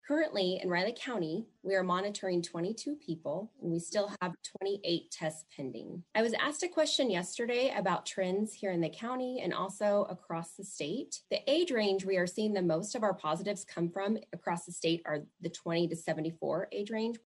During the daily Riley County Health Department press conference, Riley County Health Director Julie  Gibbs gave an update on the status of COVID-19 in Manhattan.